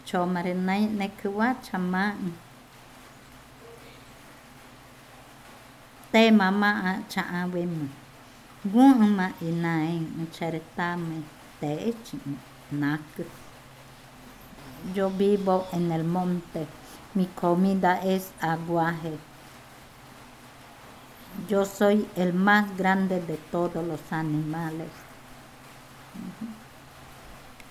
Cushillococha